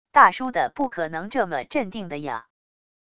大叔的不可能这么镇定的啊wav音频